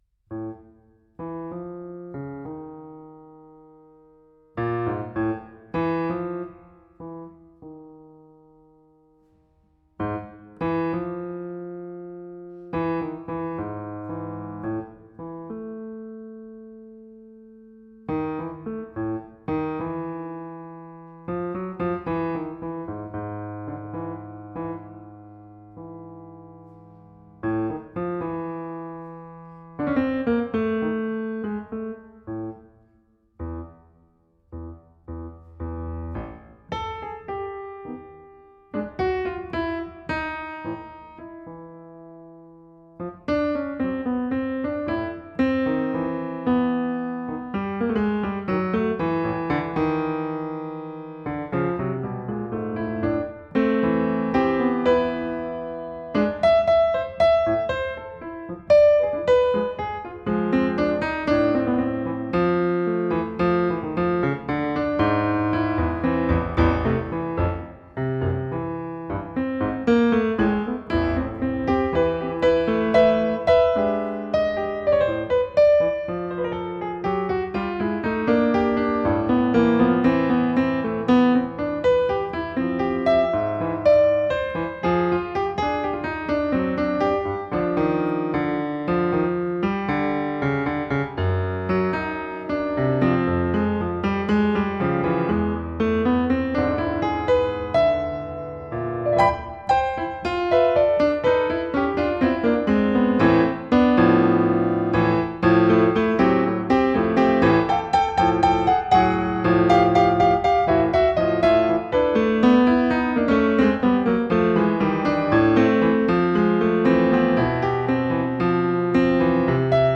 im Konzerthaus der Musikhochschule Detmold